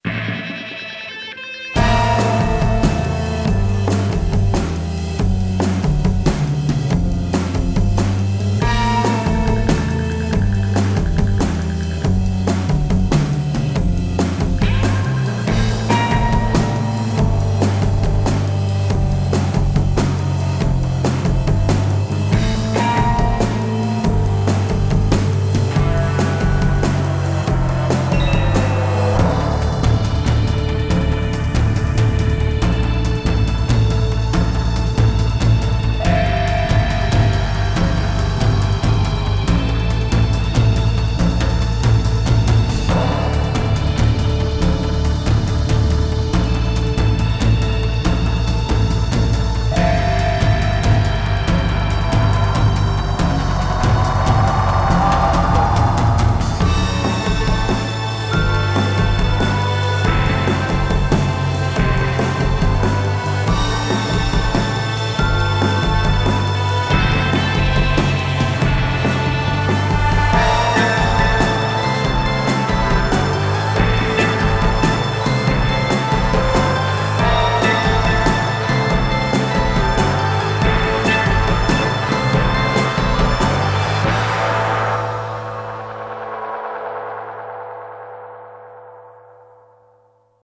• Качество: 320, Stereo
саундтреки
без слов
тревожные
alternative
Rock